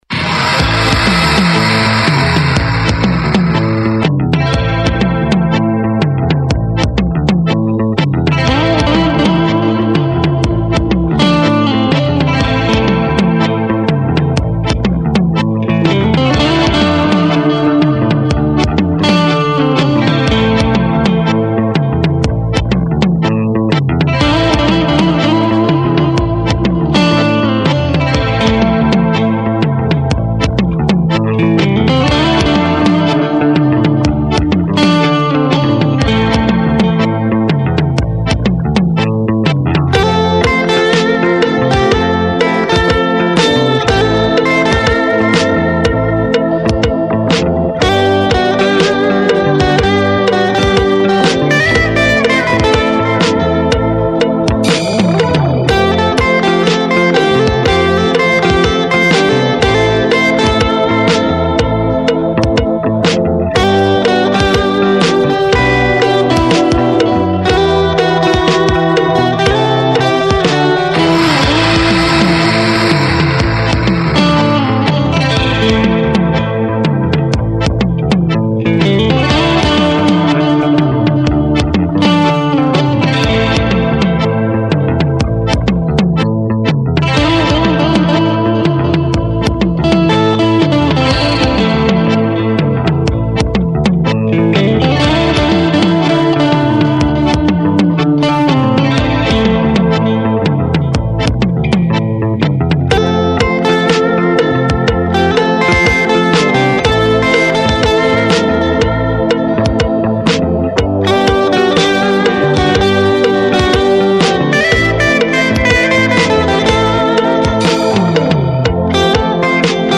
in meinem Heimstudio.